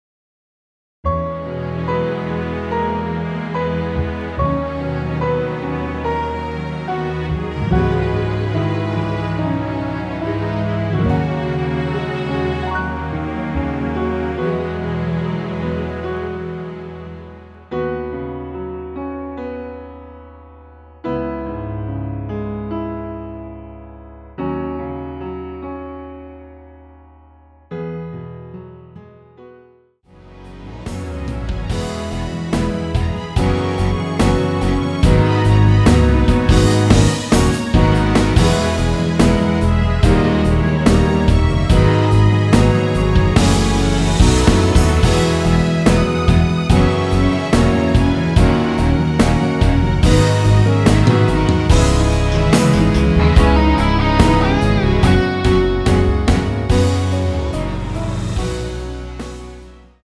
원키에서 (-3)내린 MR 입니다.
앞부분30초, 뒷부분30초씩 편집해서 올려 드리고 있습니다.
중간에 음이 끈어지고 다시 나오는 이유는